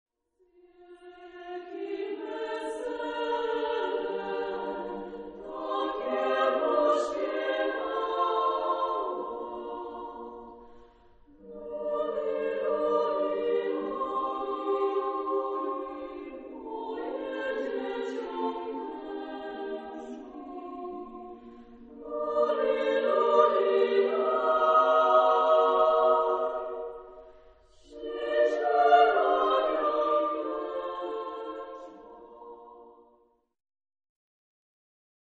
Genre-Style-Form: Christmas song ; Partsong
Mood of the piece: andante cantabile ; calm
Type of Choir: SSAA  (4 children OR women voices )
Tonality: B flat major